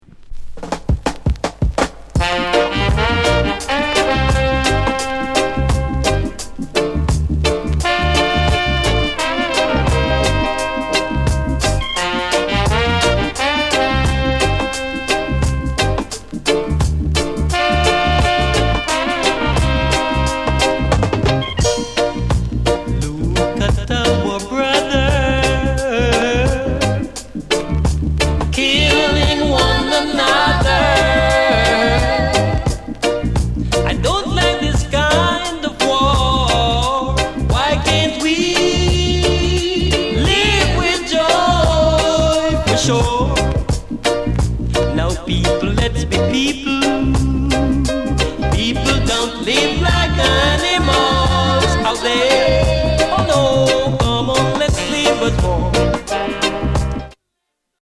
NICE VOCAL